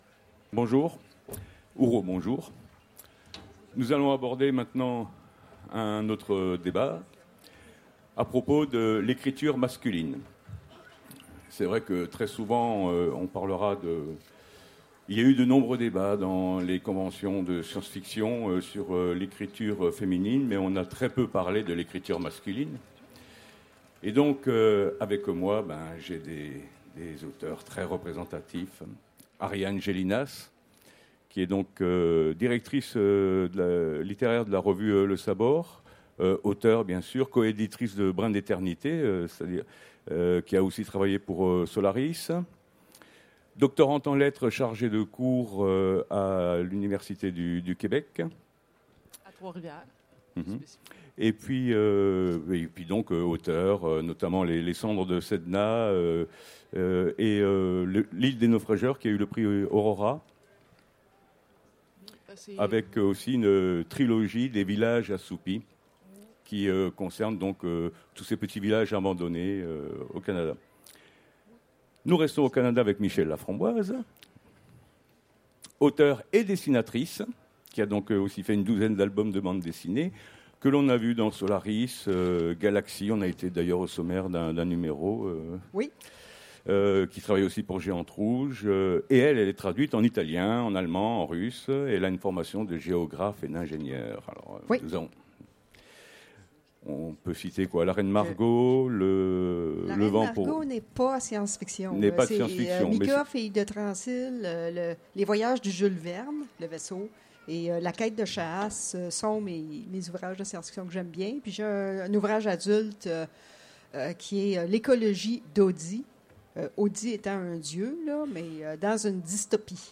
Utopiales 2016 : Conférence Mécanismes de la science-fiction masculine